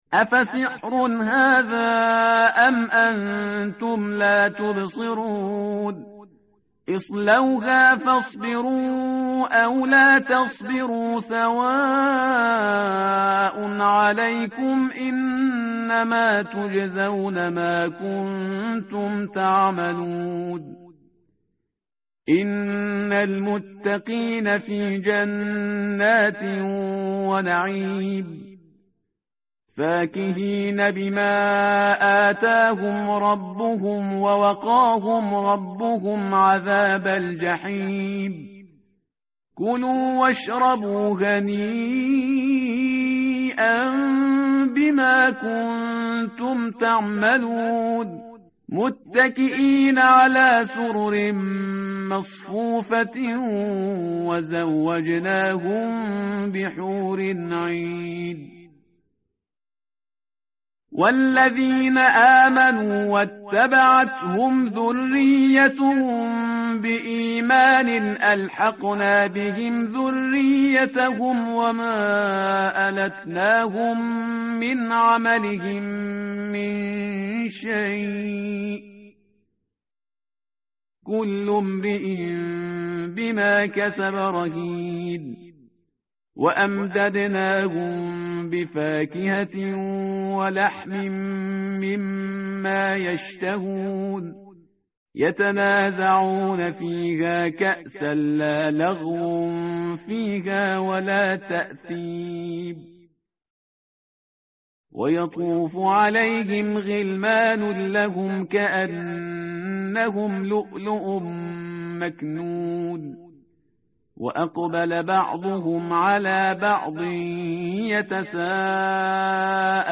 tartil_parhizgar_page_524.mp3